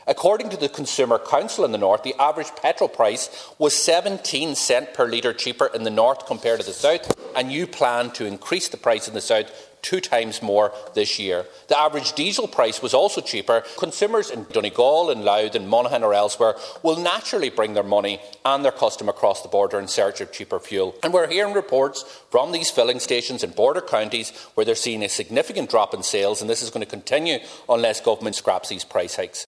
Moving a Sinn Fein motion calling for the increases to be scrapped, Deputy Pearse Doherty told the Dail the disparity in prices North and South will grow wider, and local businesses will suffer………..